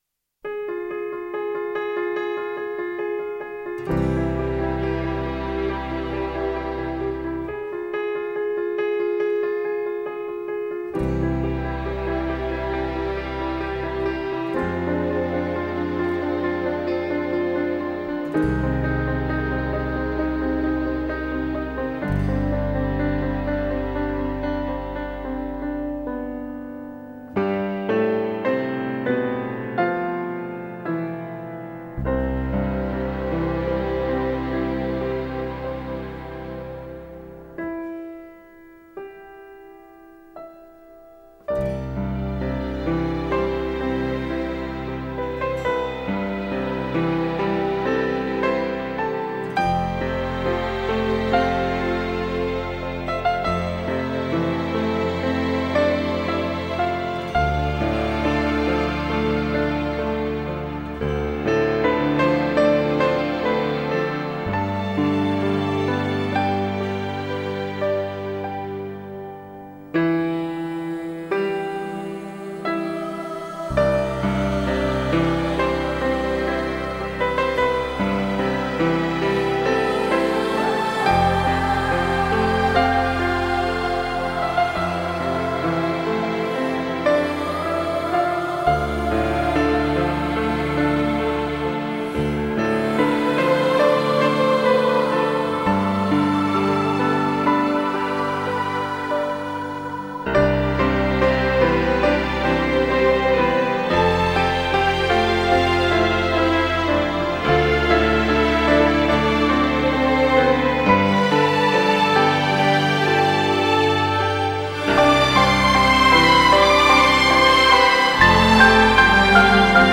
radiomarelamaddalena / STRUMENTALE / PIANO /